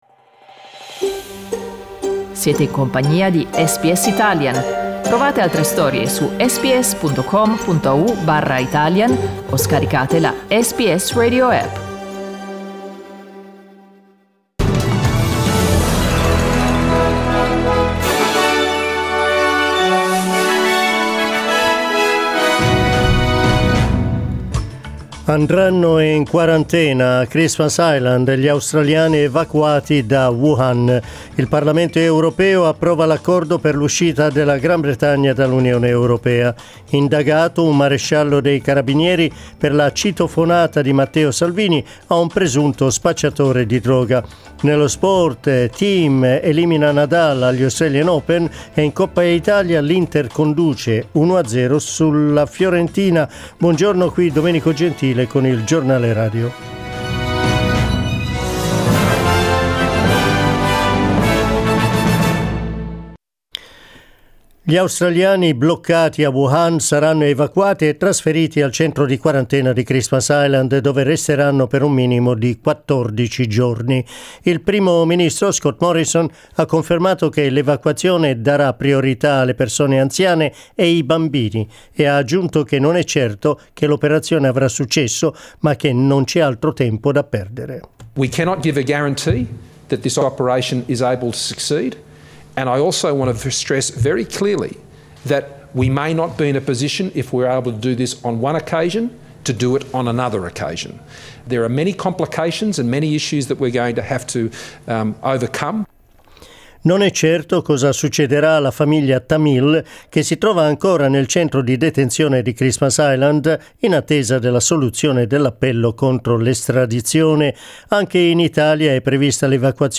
Our news bulletin (in Italian).